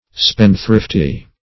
Spendthrifty \Spend"thrift`y\, a.
spendthrifty.mp3